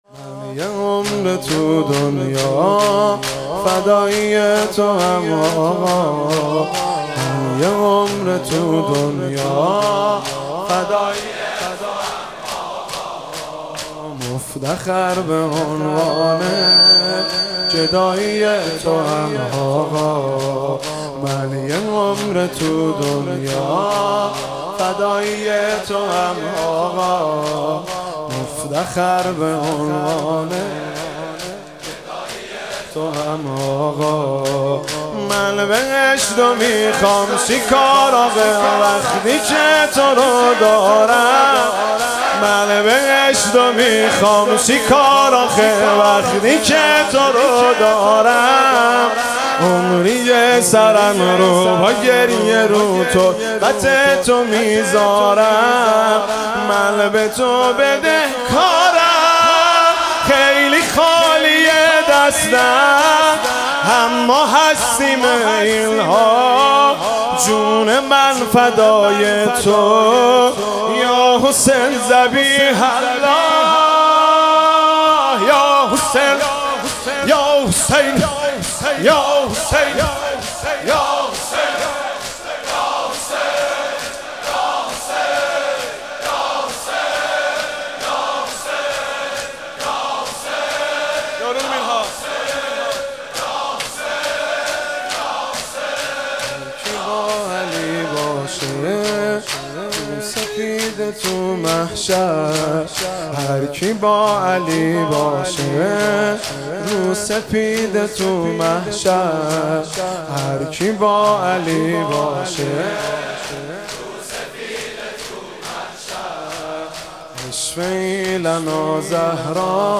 شب سوم فاطمیه 96 - شور - من یه عمر تو دنیا فدایی تو ام آقا